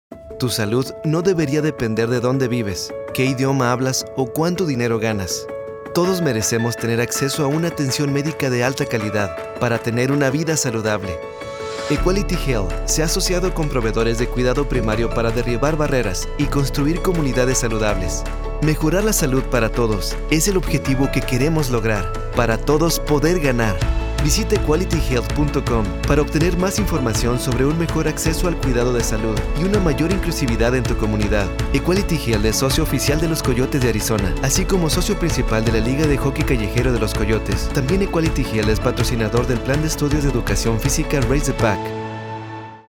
Spanisch (Mexikanisch)
Kommerzielle Demo
BaritonHoch
JungKonversationGlaubhaftNatürlichAuthentischBeiläufigEmotionalSchlauModernFrischFreundlichRuhigNeutral